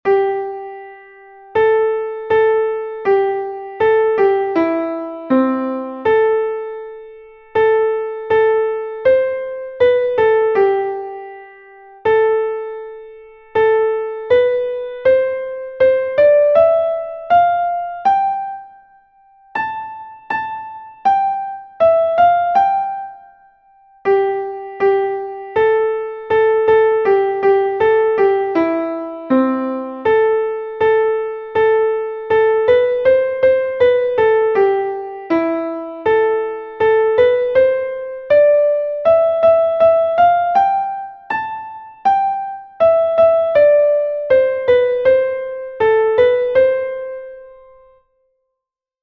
Exercise 3: 4/4 time signature.